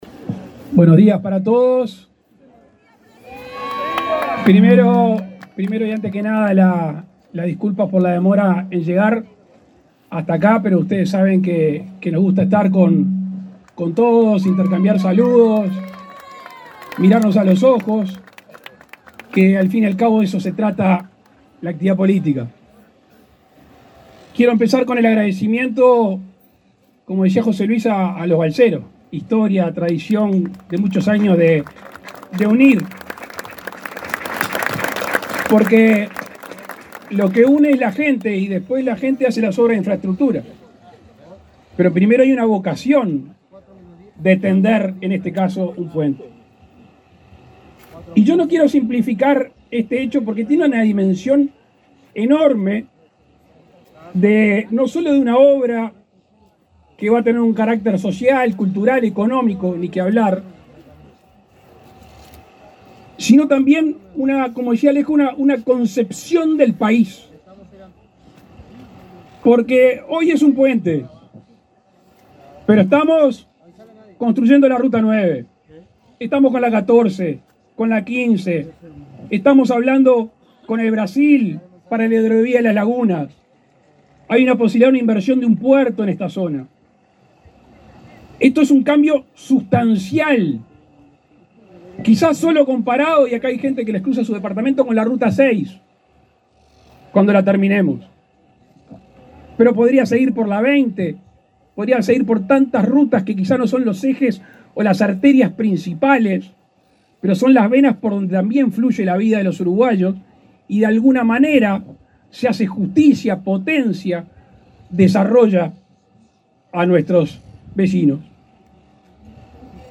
Palabras del presidente Luis Lacalle Pou
El presidente Luis Lacalle Pou encabezó el acto de inauguración del puente sobre el río Cebollatí que unirá las localidades de La Charqueada, en el